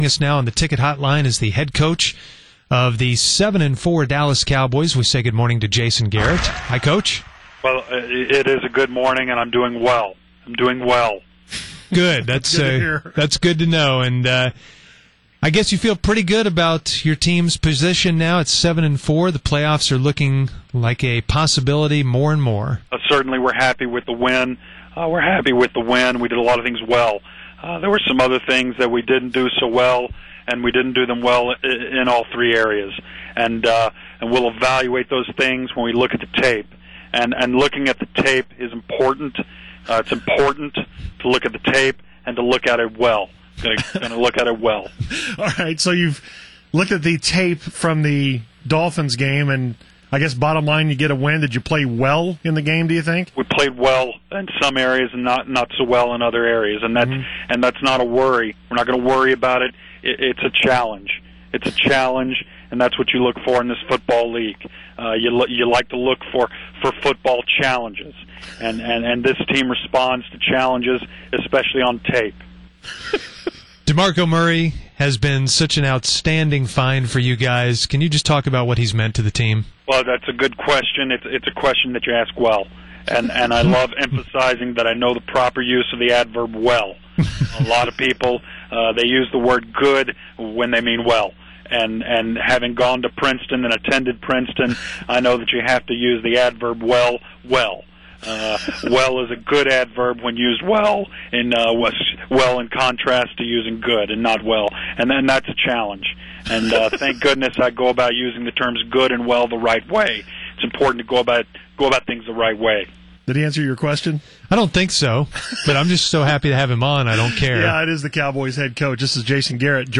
fake-jason-garrett.mp3